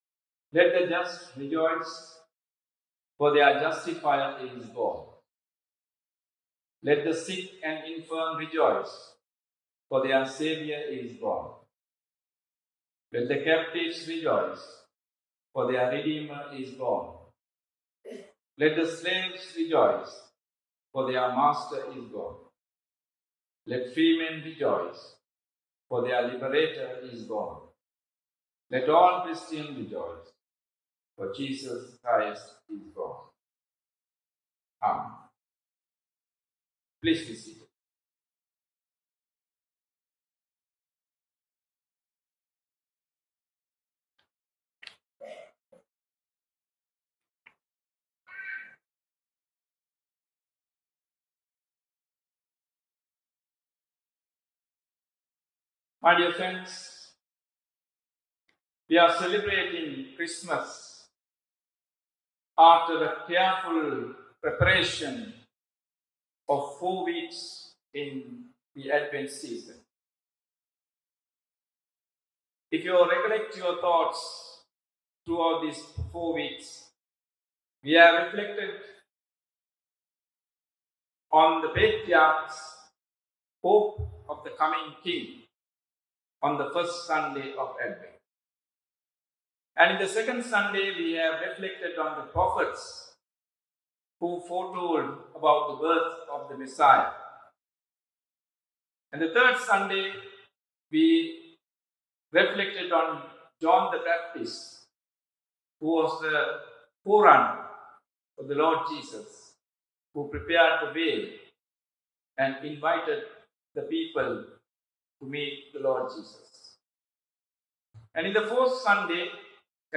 Celebrating Christmas with Purpose Today’s Sermon is from Luke 2:1-20 We celebrate Christmas after a careful four-week preparation, reflecting on the Patriarchs’ hope for the coming King, the Prophets’ prophecies about the birth of the Savior, the preparation of the way by John the Baptist, and Mother Mary who bore and presented Jesus to the